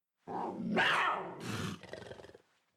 POUNCE_WARNING.mp3